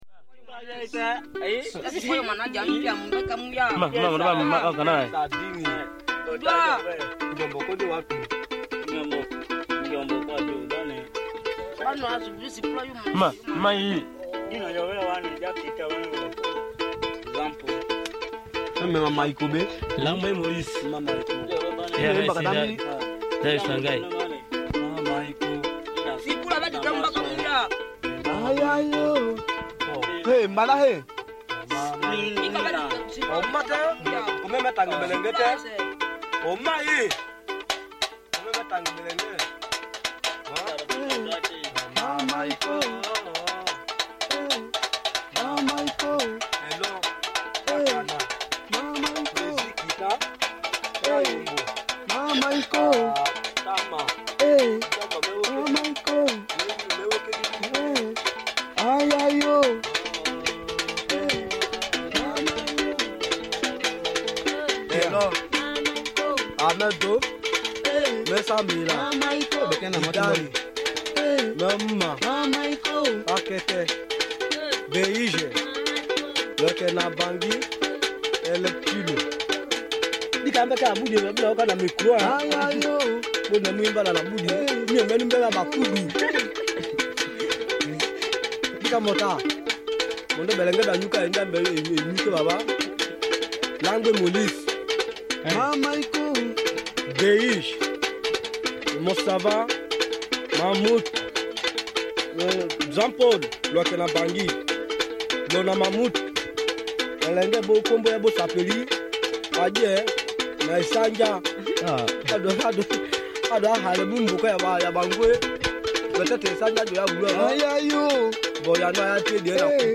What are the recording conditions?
cassette tape and digital audio tape recordings